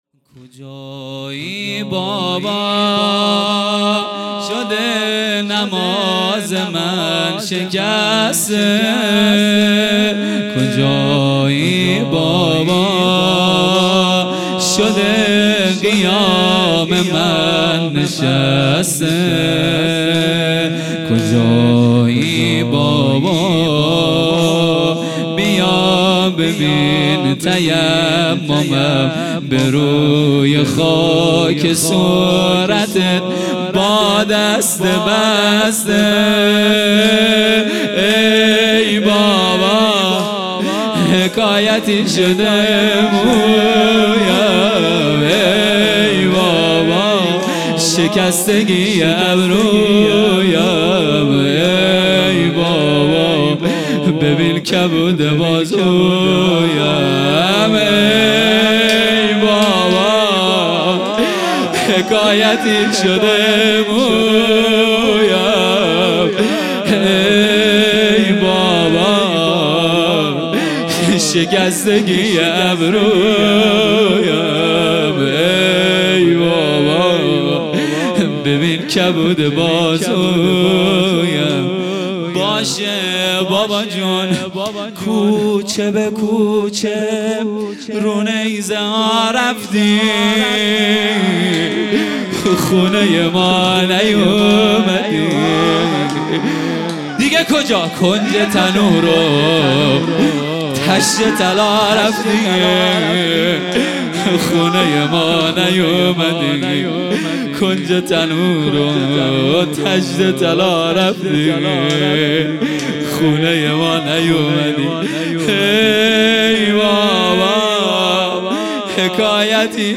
زمینه | کجایی بابا، شده نماز من شکسته | پنج شنبه ۱۸ شهریور ۱۴۰۰
جلسه‌ هفتگی | شهادت حضرت رقیه(ع) | پنج شنبه ۱۸ شهریور ۱۴۰۰